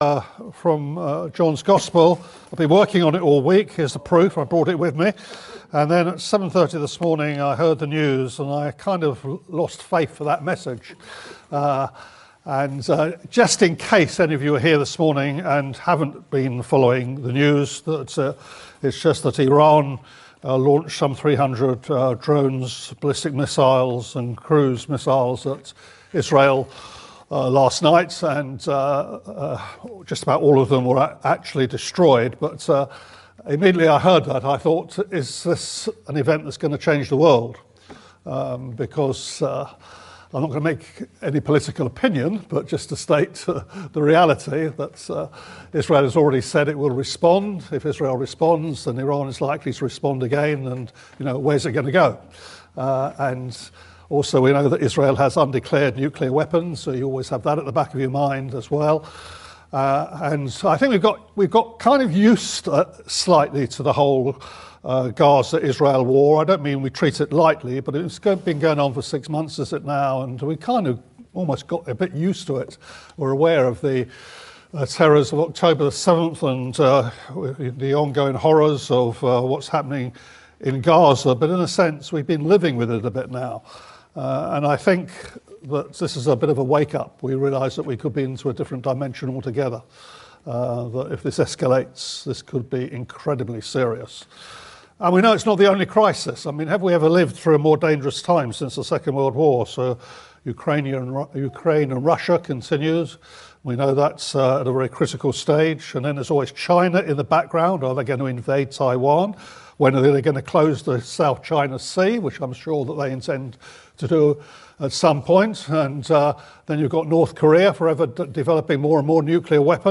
This sermon is a timely encouragement to lay hold of the glorious and certain hope that believers have with Jesus’ second coming.